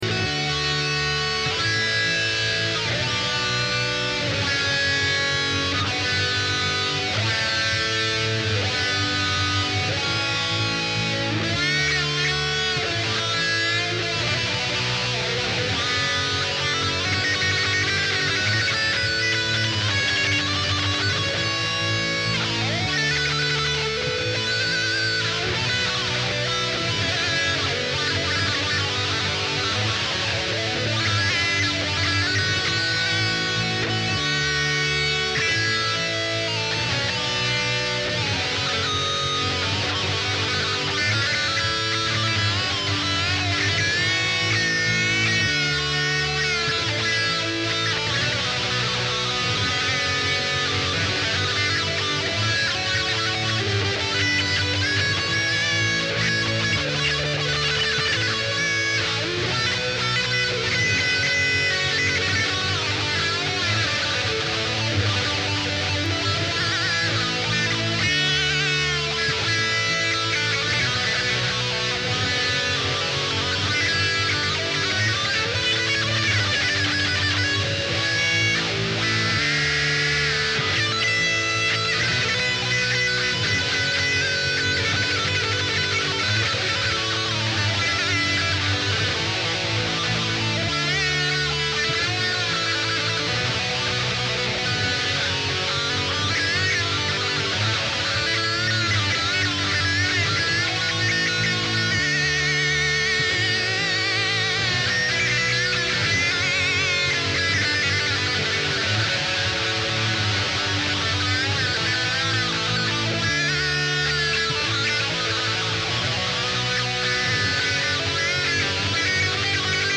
Ze względu na ciekawą progresję akordów: D-dur, A-dur, h-moll, fis-moll, G-dur, D-dur, G-dur i A-dur.
Dodajemy odpowiednio dużo przesteru i zamiast pełnych akordów gramy na gitarze tylko power chordy (lub kwarty). Taki podkład nagrałem sobie na looperze. Jak następnie dorobić do tego metalowe solo?
Weźmy więc skalę h-moll pentatoniczną, która na gryfie gitary układa się dość ergonomicznie:
Do skali h-moll pentatonicznej warto dorzucić chwilami pełne h-moll naturalne.
Na  przykład nutka fis brzmi w tym kontekście ciekawiej jako podciągnięte e (techniką bendingu) o dwa półtony.